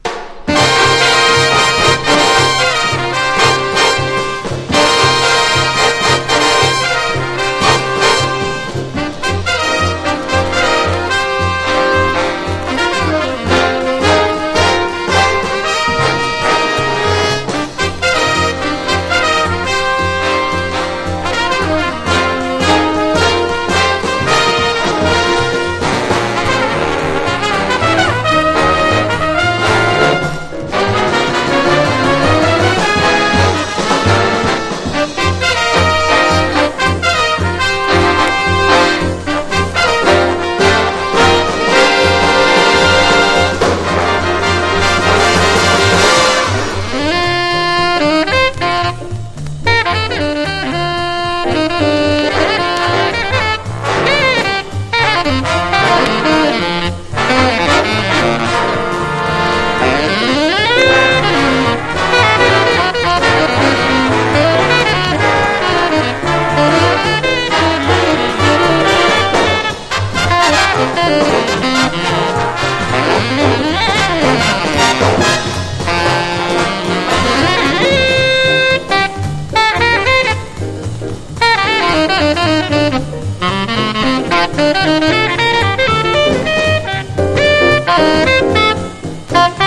BIGBAND / SWING# ハードバップ